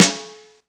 Snr Rok 02.wav